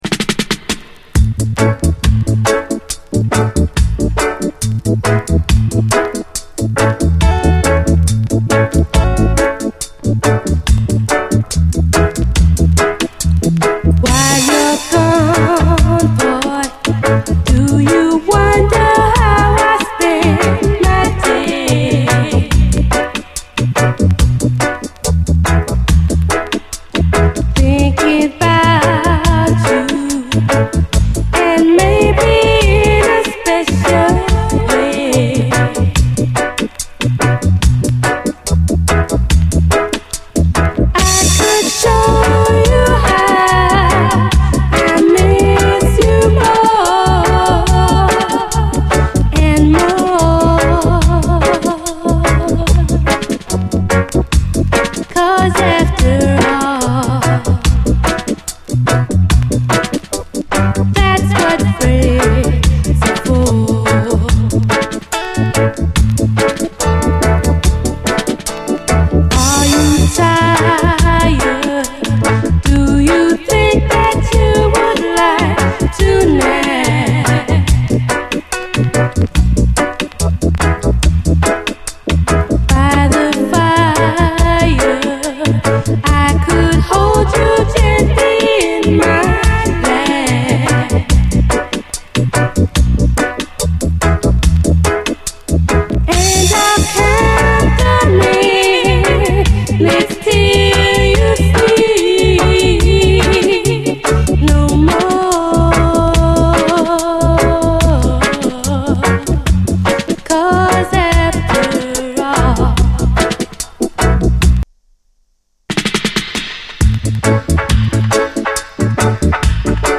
REGGAE, 7INCH